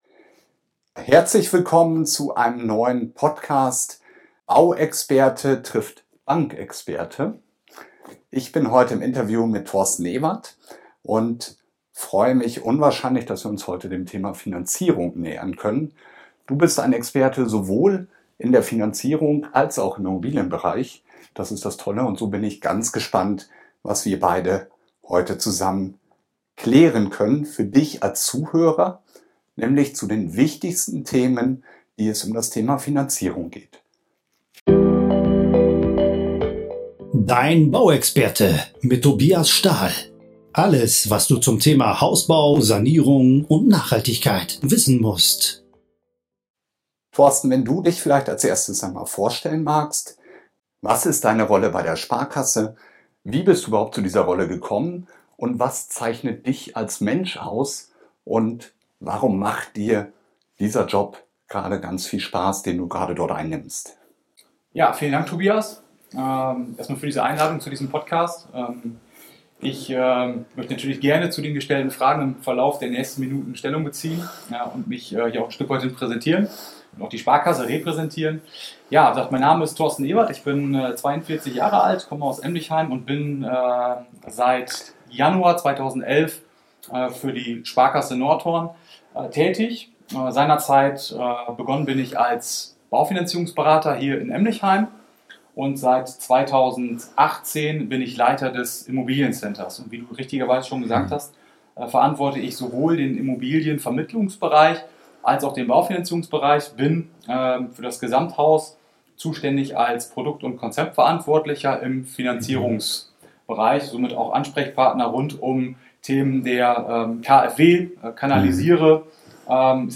Nr. 68 - Die 10 häufigsten Fragen zur Baufinanzierung! Interview